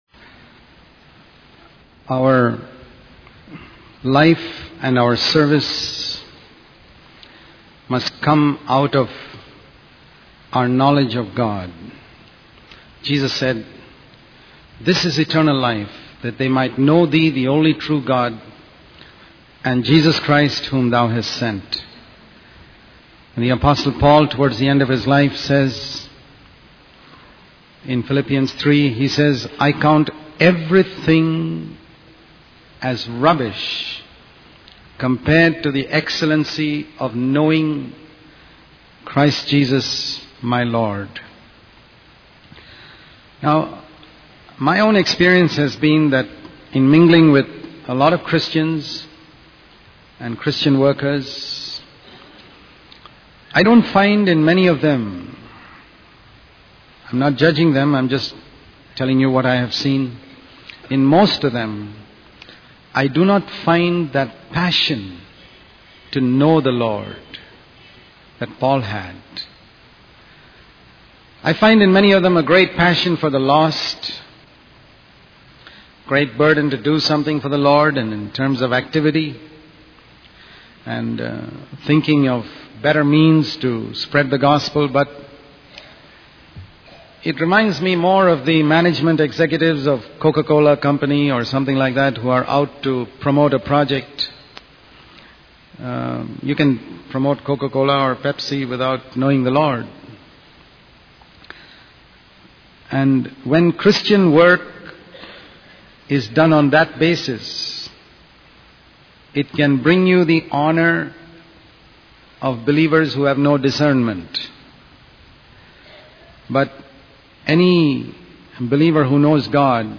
In this sermon, the speaker emphasizes the confidence that comes from knowing that God has already seen and made provision for every pitfall and snare that Satan and evil men may try to use against us.